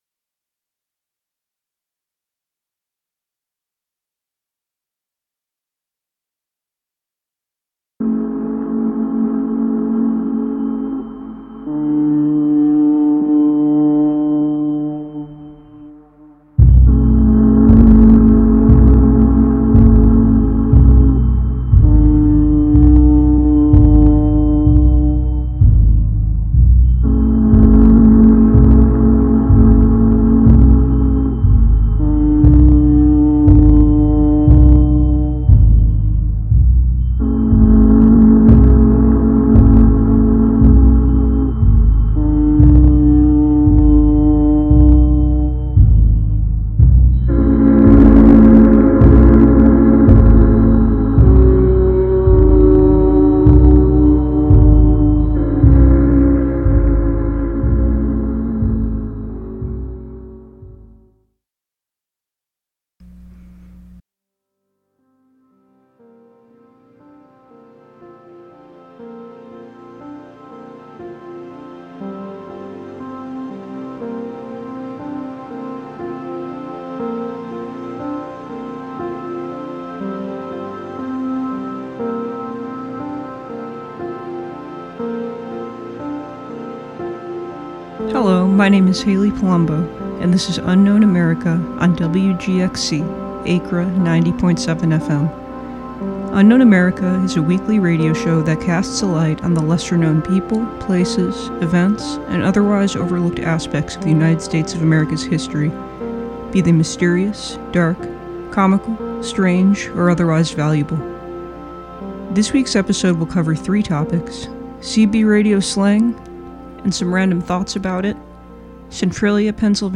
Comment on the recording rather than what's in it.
Through occasional interviews, on-site reporting, frantically obsessive research, and personal accounts, the listener will emerge out the other side just a bit wiser and more curious about the forgotten footnotes of history that make America fascinating, curious, and complicated.